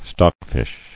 [stock·fish]